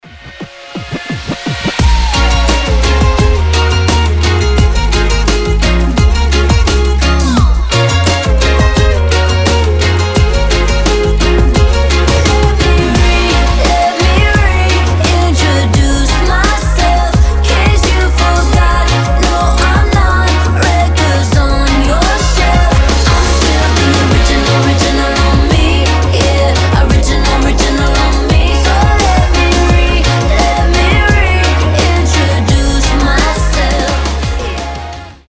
• Качество: 320, Stereo
гитара
заводные
регги